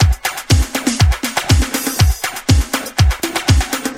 • 121 Bpm Drum Groove B Key.wav
Free drum beat - kick tuned to the B note. Loudest frequency: 1919Hz
121-bpm-drum-groove-b-key-UR4.wav